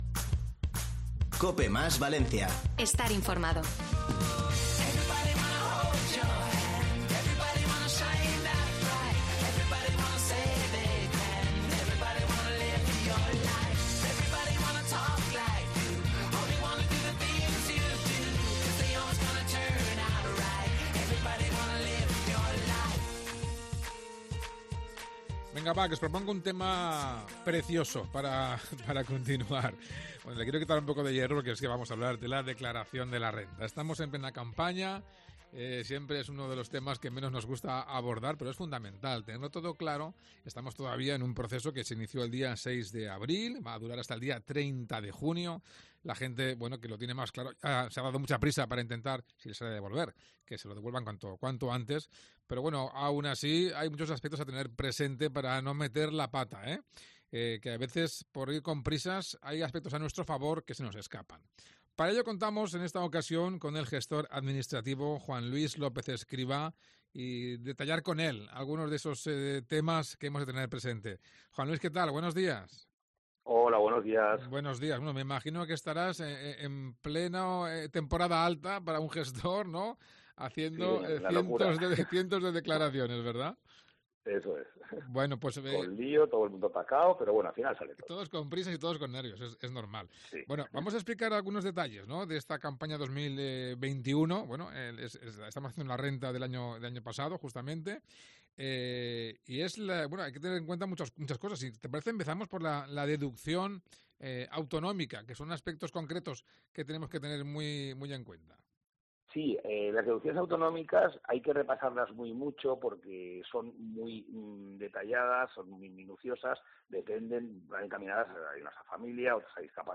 Mediodía COPE MÁS Valencia | Entrevista Colegio de Gestores Administrativos de Valencia